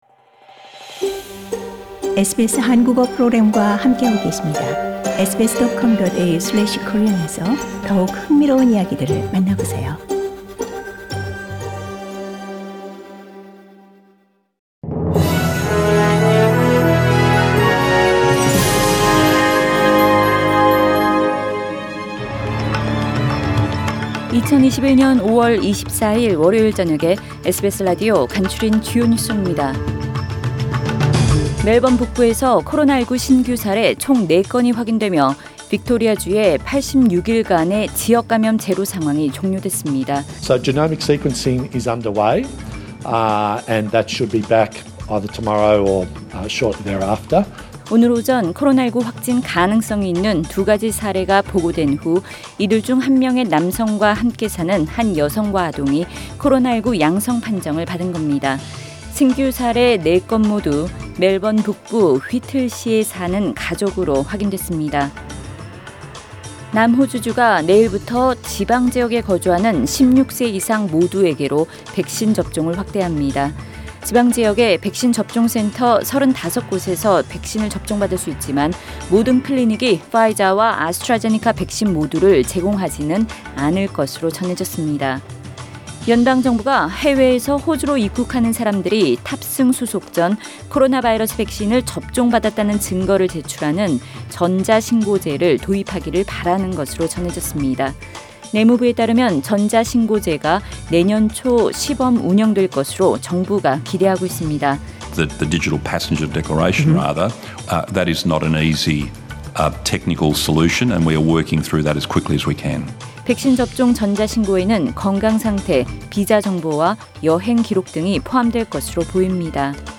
2021년 5월 24일 월요일 저녁의 SBS 뉴스 아우트라인입니다.